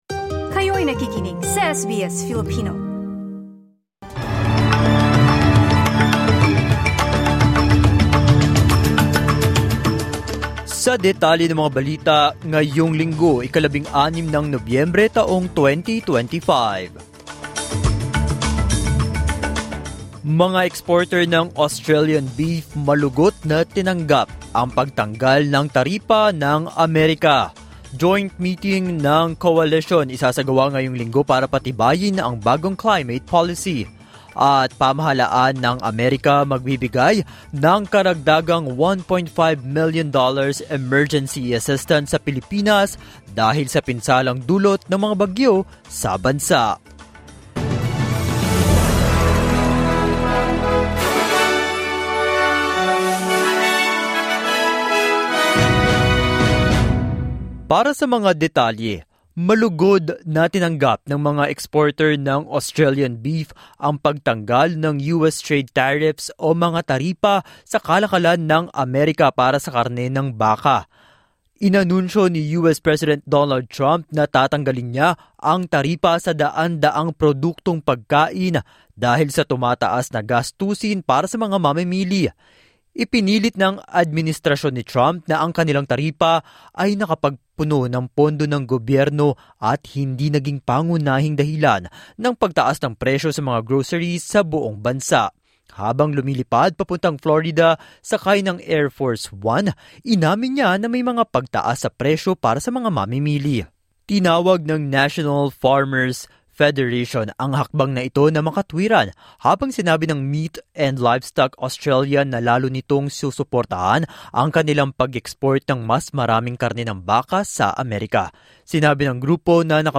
SBS News in Filipino, Sunday 16 November 2025